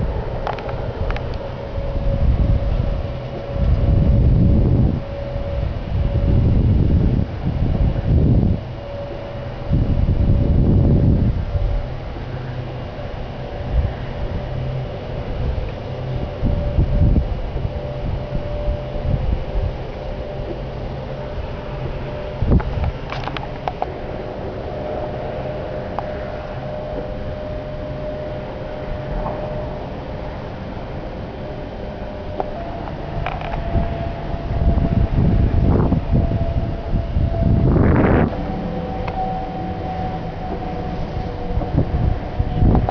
Abb. 04: Geräusche eines Windgenerators mit Getriebe, ENRON Wind 1,5,
Die horizontalen dünnen Linien im Diagramm stammen vom Getriebe. Die Aufzeichnung enthält Bereiche mit unterschiedlichen Windgeschwindigkeiten und rasch wechselnder Windstärke (Böen)